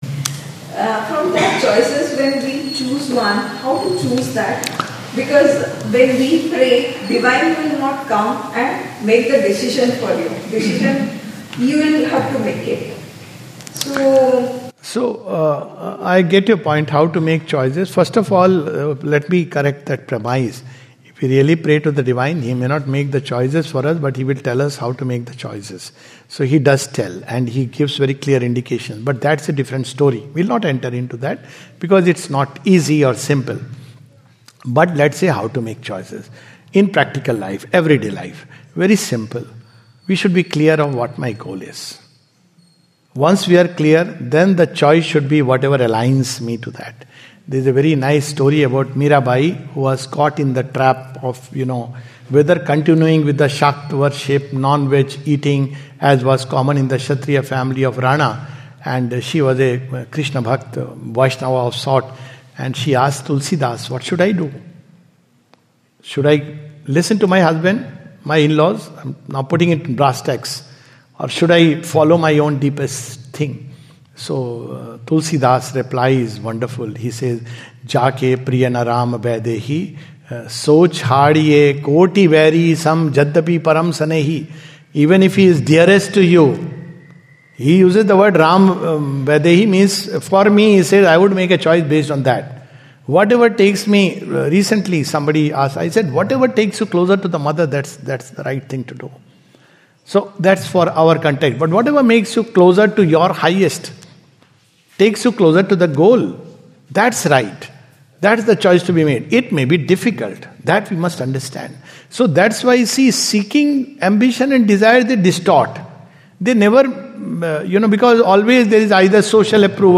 From a Q and A session organised by the Sri Aurobindo Society at Sharanam.